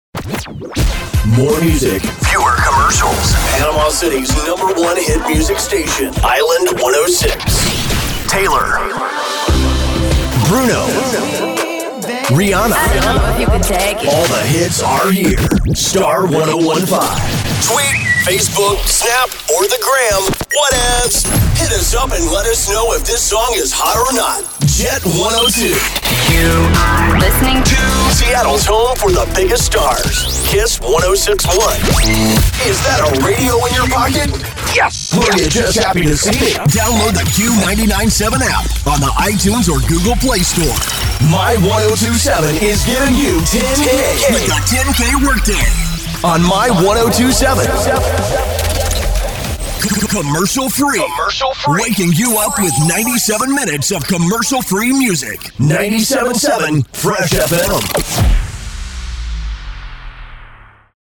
Male
Yng Adult (18-29), Adult (30-50)
★★★ EXPERIENCED PROFESSIONAL ★★★ The guy next door, with a natural approachable sound.
Radio / TV Imaging
C-H-R Radio Imaging